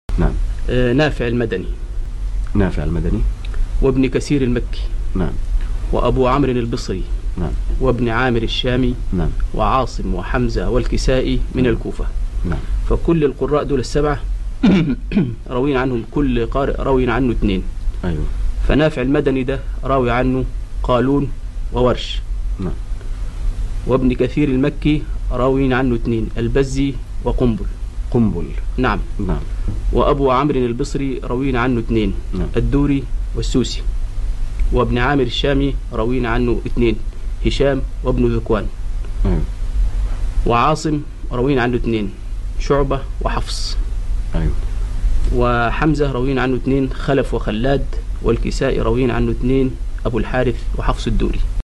The seven modes of recitation sound effects free download
The seven modes of recitation presented by Qari ‘Abdul Basti, may Allah have mercy on him!